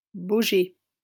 Baugé (French pronunciation: [boʒe]